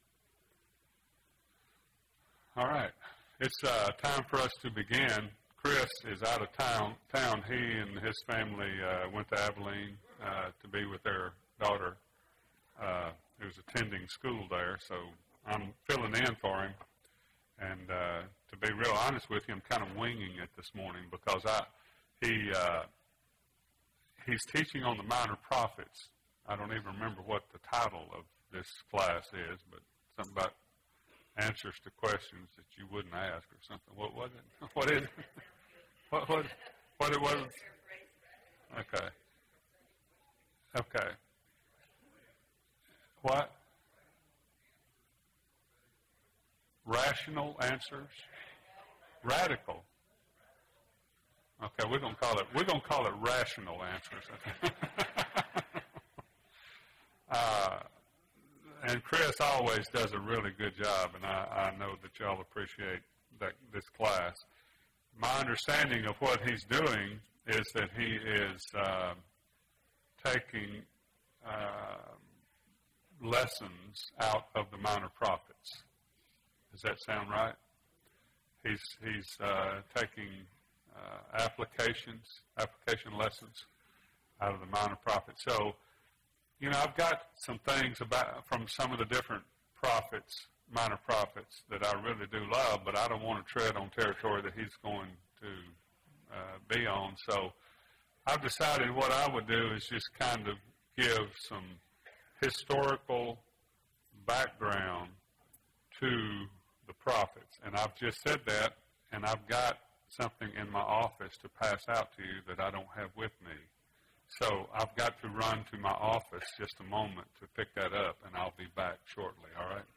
Overview of the Prophets (2 of 12) – Bible Lesson Recording